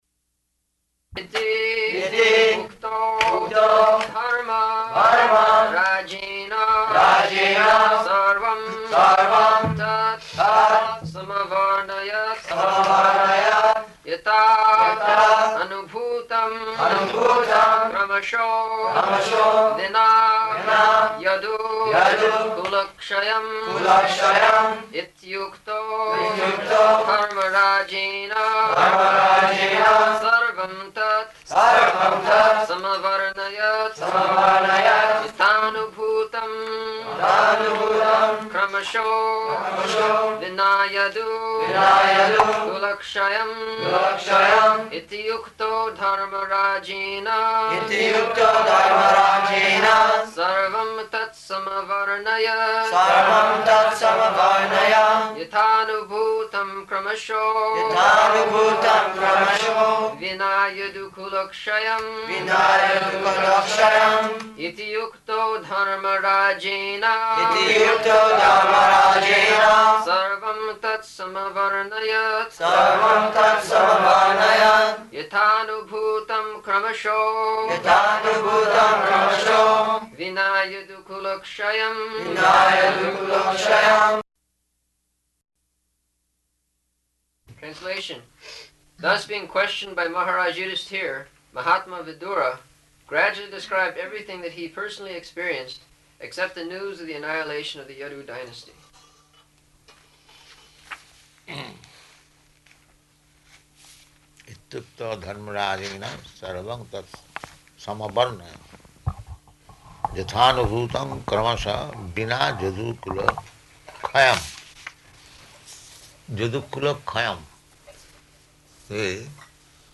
June 3rd 1974 Location: Geneva Audio file
[leads chanting of verse, etc.] [devotees repeat]